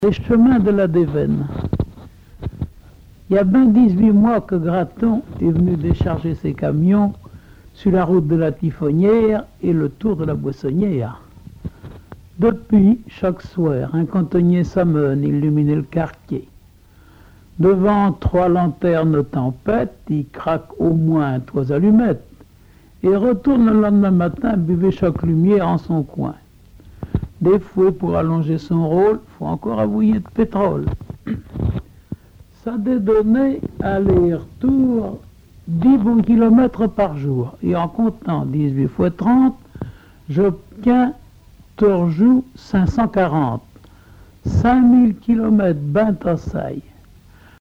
Localisation Chauvé
Langue Patois local
Genre récit